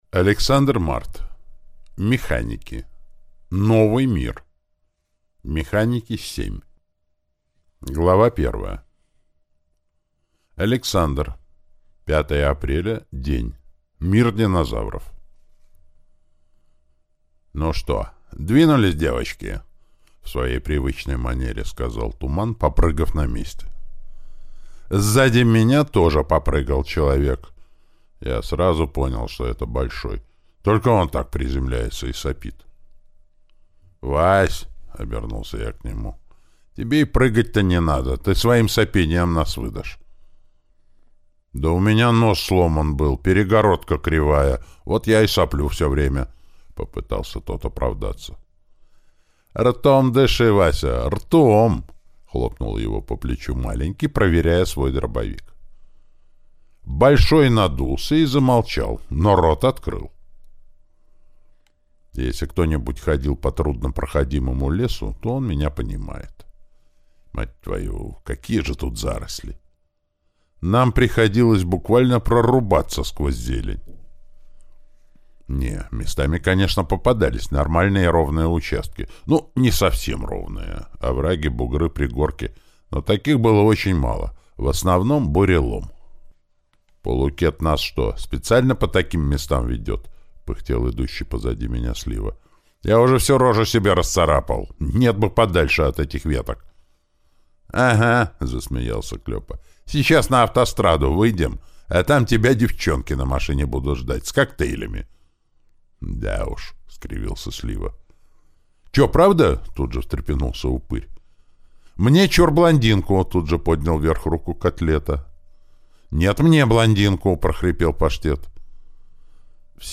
Аудиокнига Механики. Новый мир | Библиотека аудиокниг